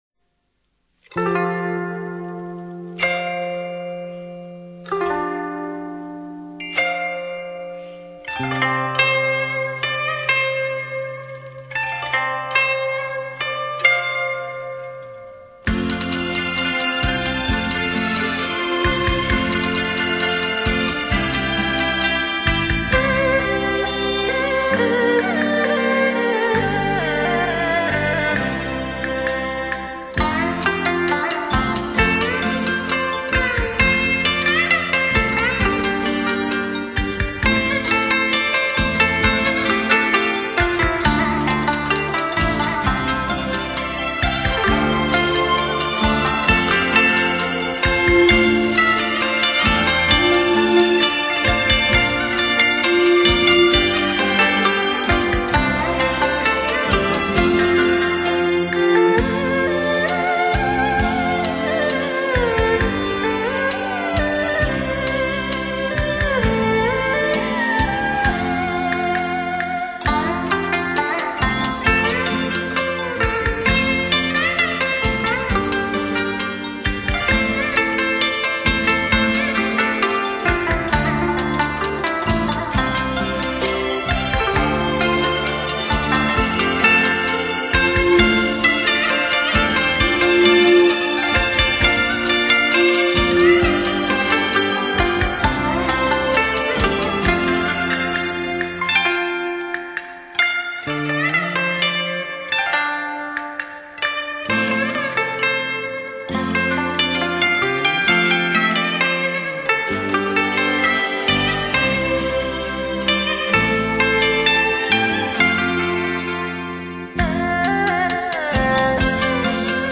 古筝独奏